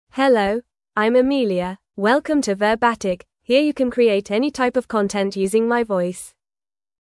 FemaleEnglish (United Kingdom)
AmeliaFemale English AI voice
Amelia is a female AI voice for English (United Kingdom).
Voice sample
Female
Amelia delivers clear pronunciation with authentic United Kingdom English intonation, making your content sound professionally produced.